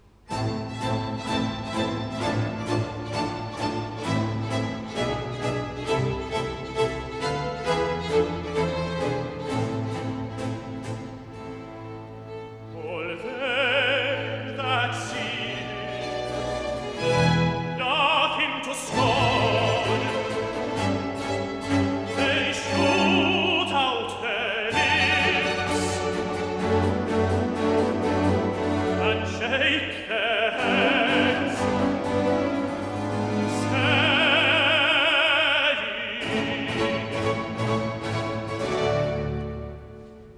Recitative-tenor